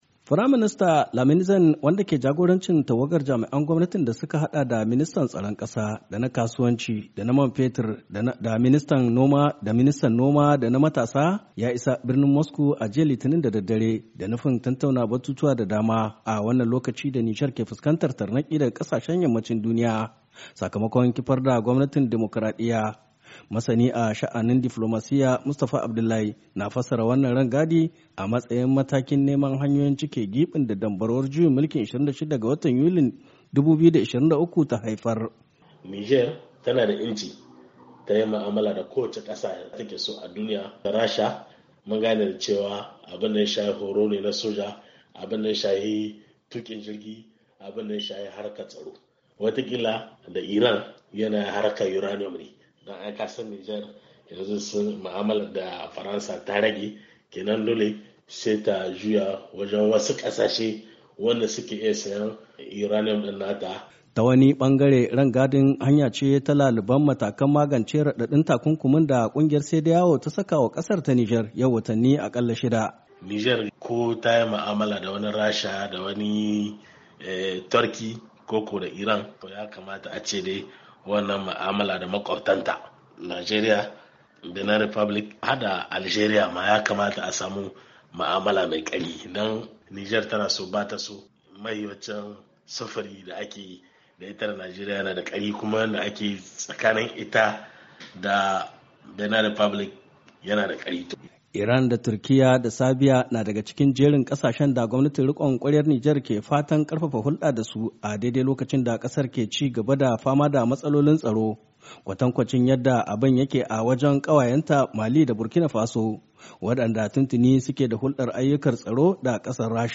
Yamai, Niger —